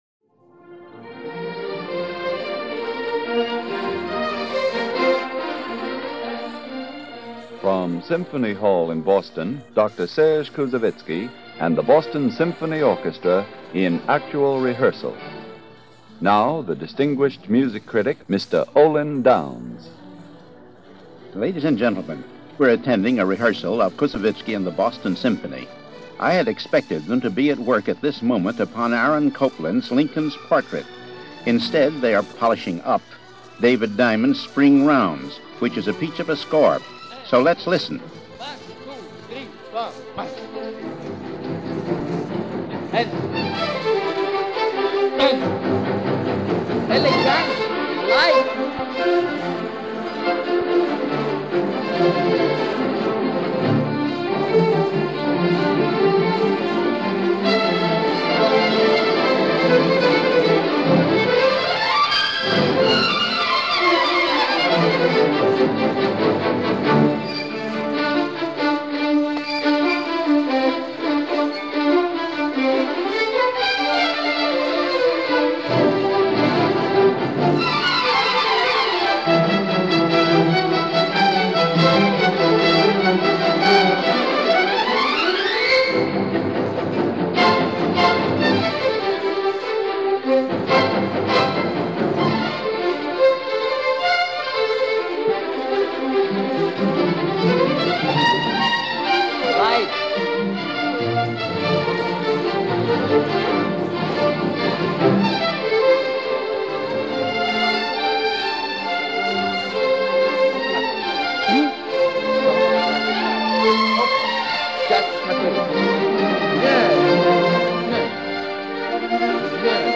Serge Koussevitsky - in rehearsal with The Boston Symphony in music of Diamond and Copland - 1949 - Past Daily Weekend Gramophone.
Boston Symphony In Rehearsal With Serge Koussevitsky, Conductor – January 10, 1949 – Gordon Skene Sound Collection
featuring Music Director Serge Koussevitsky leading the orchestra in rehearsals